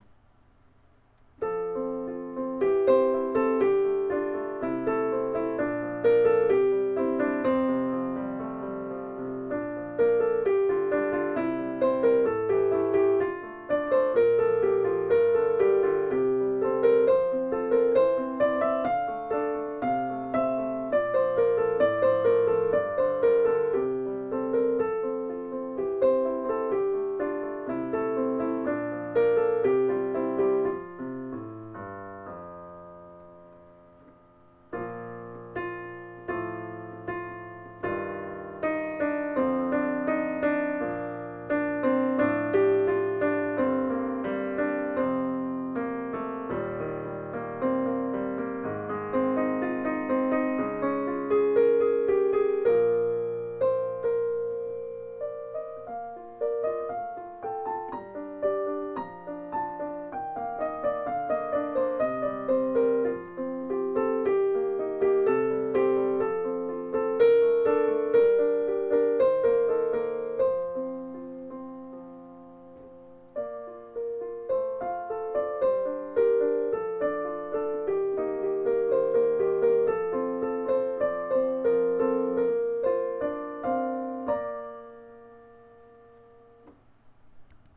Please keep in mind, I recorded these on my electric piano using a hand-held digital recorder.  Not exactly a professional job!